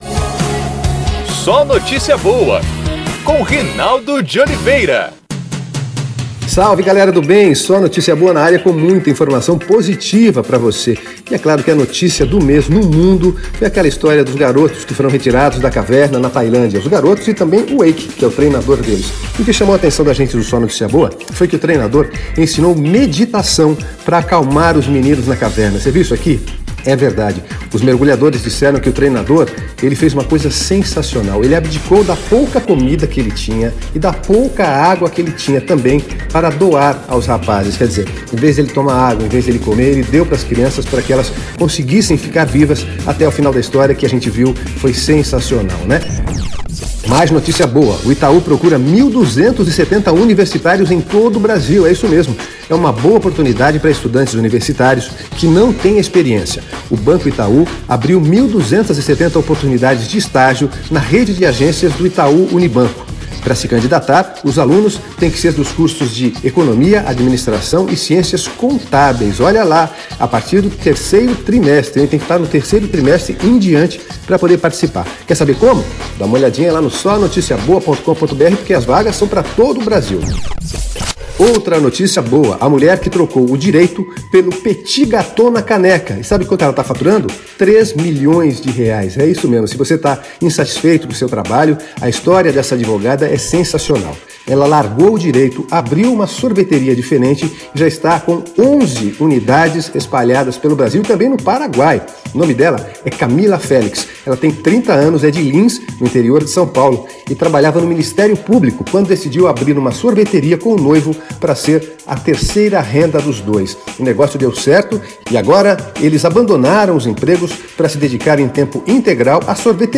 É o podcast SóNotíciaBoa, nosso programa de rádio.